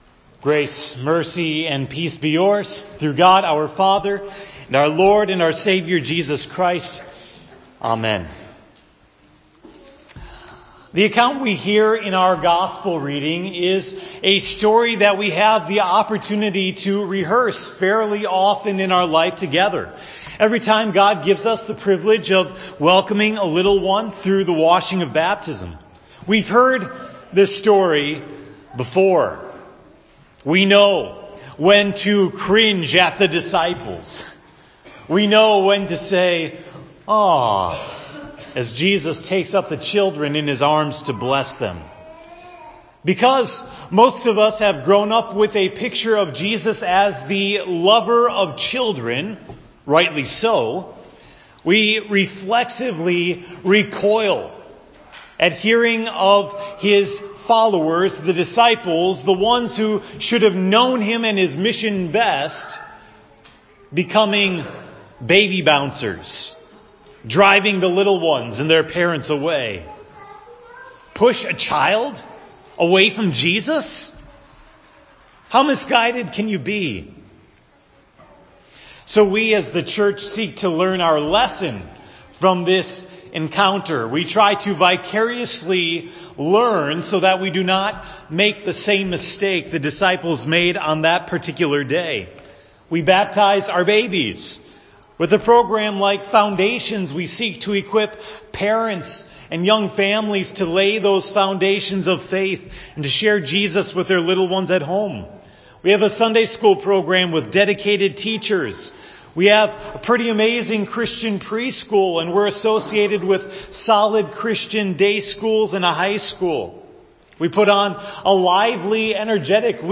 The sermon for October 7, 2018 at Hope Text: Mark 10:13-16